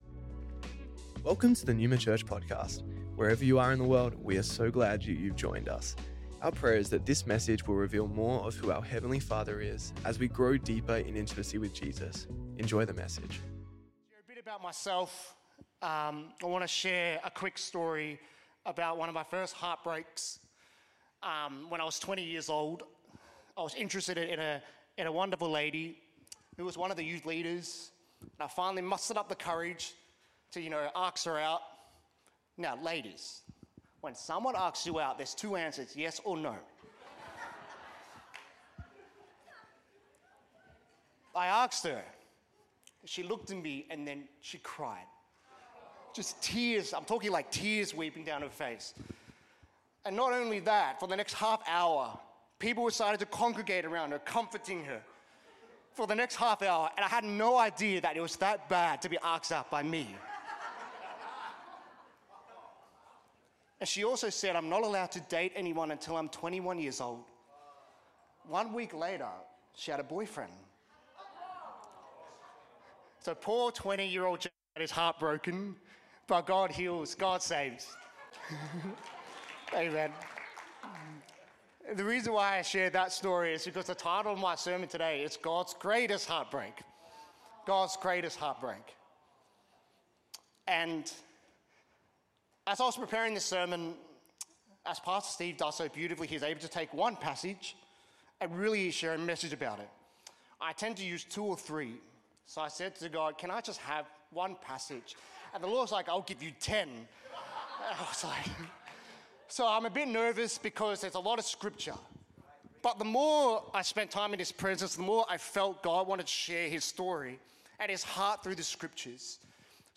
Originally Recorded at the 10AM service on Sunday 24th November 2024&nbsp